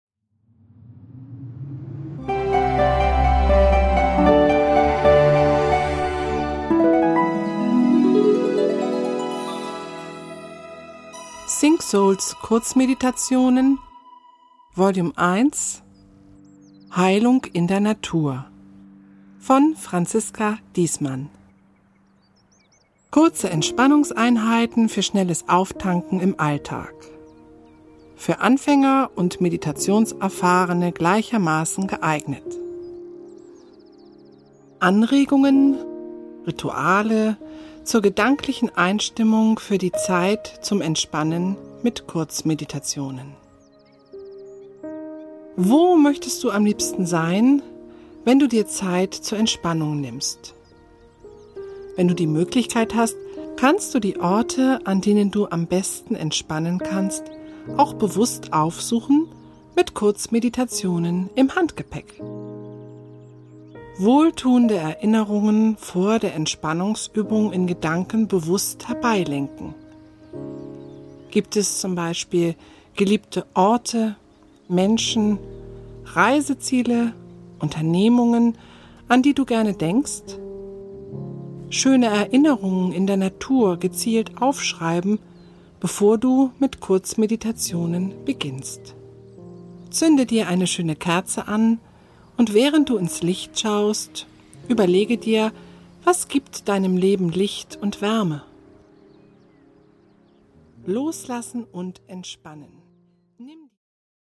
Eine innere Reise zur Ruhe mit entspannenden und bewußtseinserweiternden Texten, untermalt mit Naturgeräuschen und weichsanften Klängen.